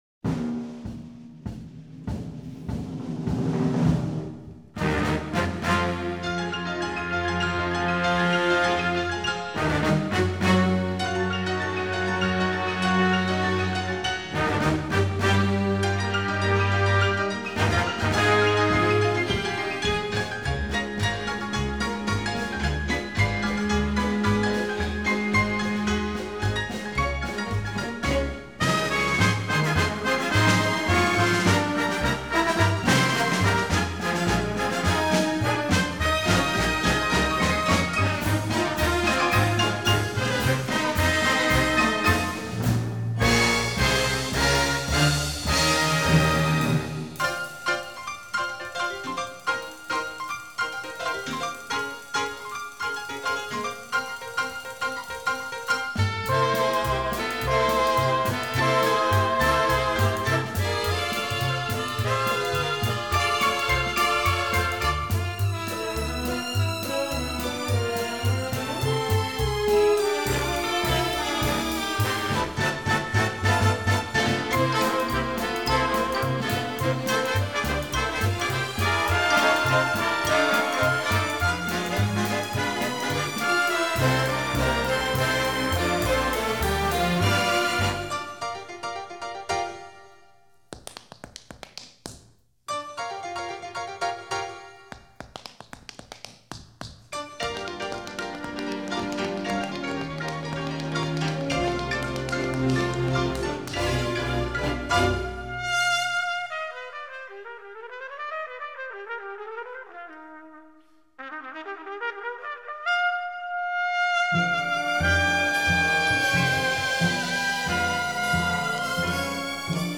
Genre: Musical /Soundtrack